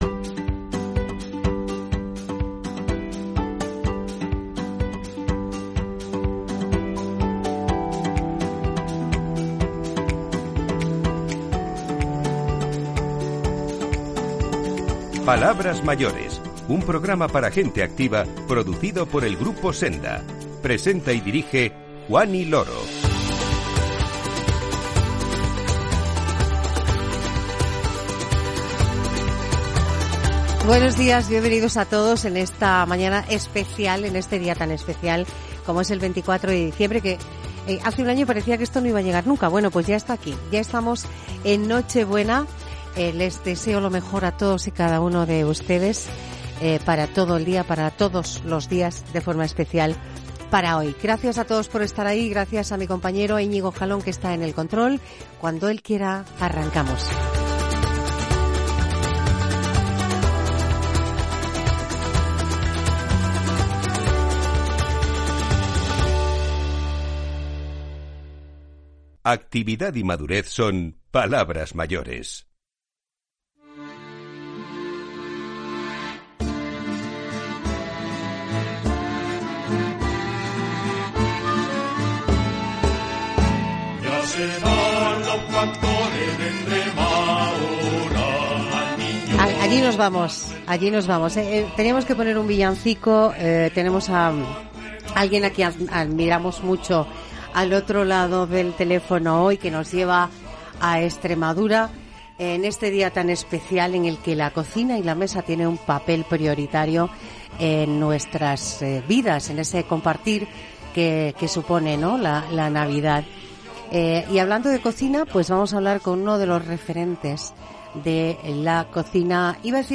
Toño Pérez, el chef de Atrio, conversa con nosotros sobre la importancia de la cocina en estas celebraciones navideñas.